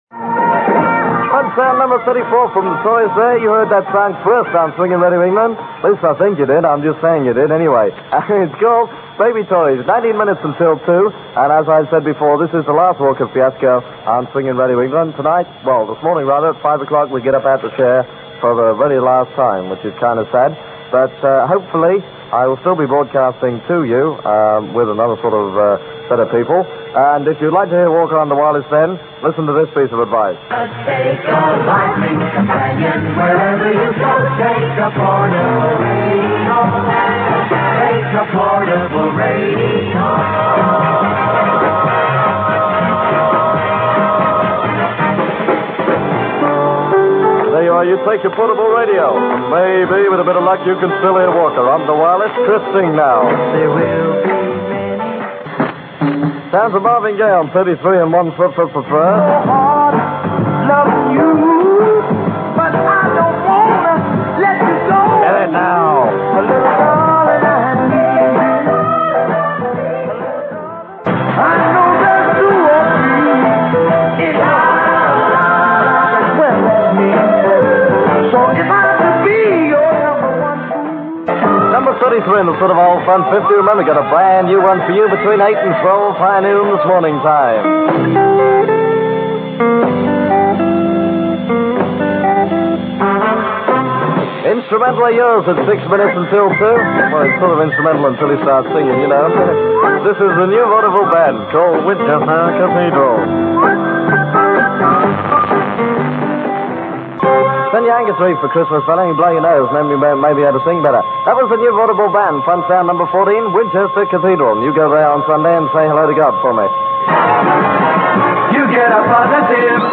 click to hear audio Johnnie Walker on his last show for Swinging Radio England, 15th October 1966. Recording courtesy of The Offshore Radio Archive (duration 2 minutes 18 seconds)